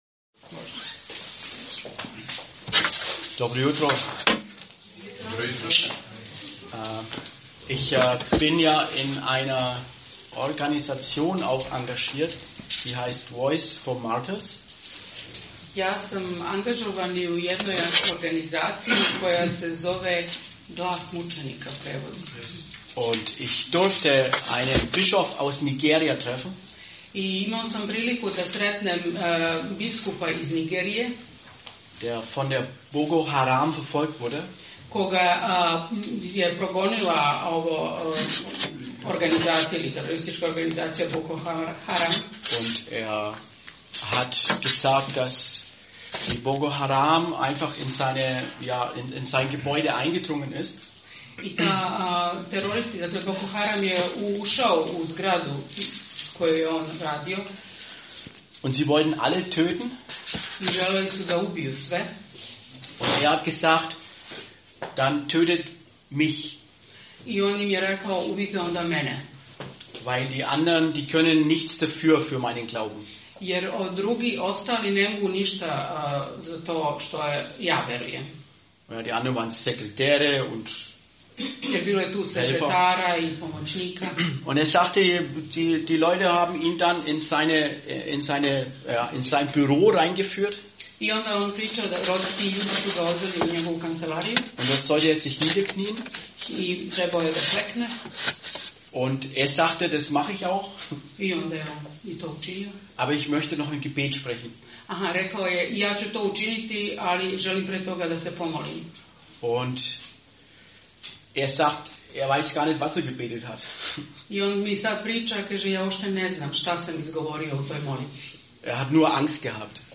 Propoved: Avramova ljubav i pouzdanje - 1. Mojsijeva 14:1-24
Serija: Avram: otac svih koji veruju | Poslušajte propoved sa našeg bogosluženja.